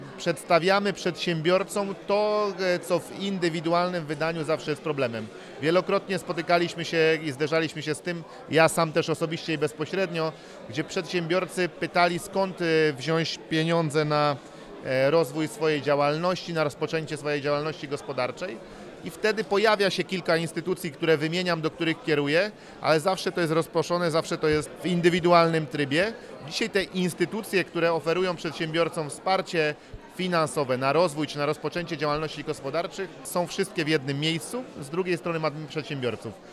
O projekcie mówi marszałek województwa dolnośląskiego – Paweł Gancarz.